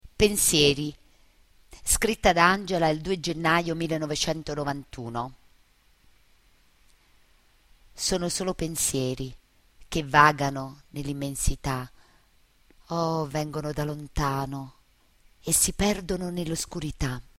Inserito in Poesie recitate da docenti